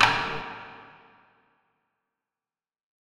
Taiko Stick (Amazing).wav